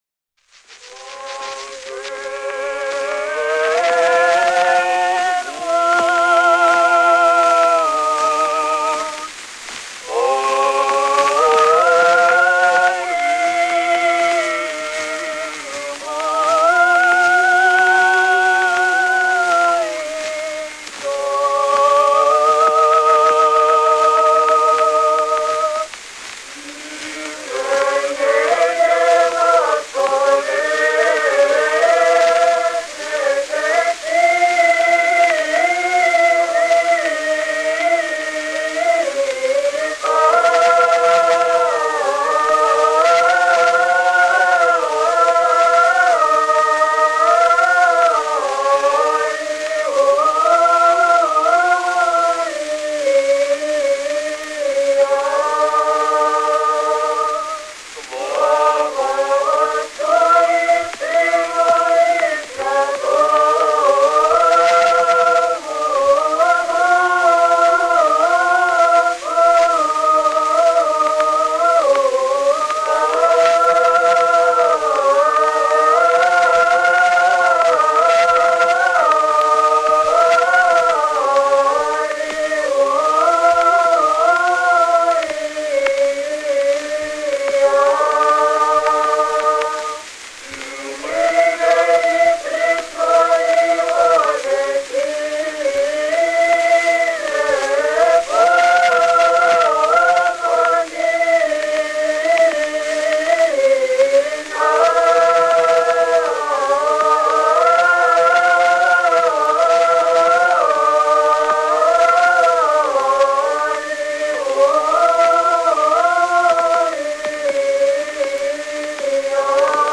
Его отличает манера звукоизвлечения, близкая к академической, интонационная устойчивость, высокий регистр пения, подвижный темп, гибкое в ритмическом плане исполнение песнопений, безукоризненный ритмический, темповый и динамический ансамбль, тембровое единство, четкая дикция, особое внимание к смыслу литургического текста.
01 Кафизма 1 «Блажен муж» знаменного роспева в исполнении подмосковного старообрядческого Морозовского хора